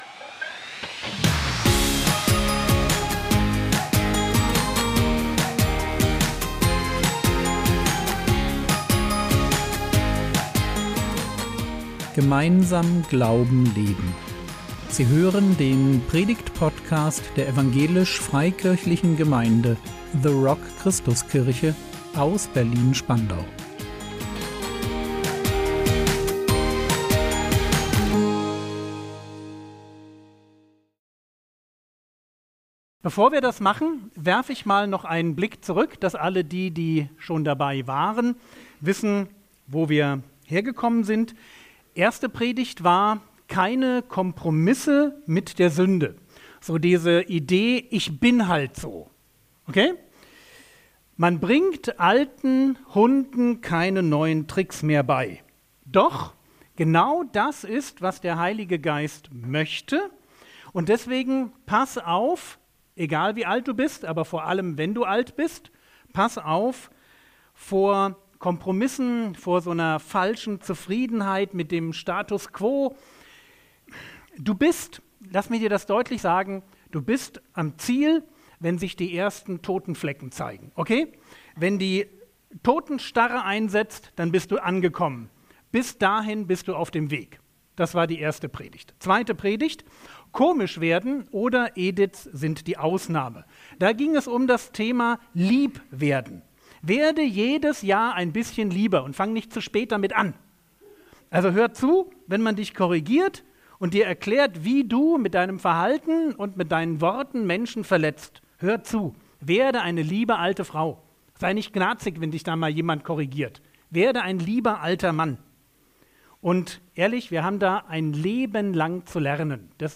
Altwerden ist gefährlich - Teil 5 | 26.01.2025 ~ Predigt Podcast der EFG The Rock Christuskirche Berlin Podcast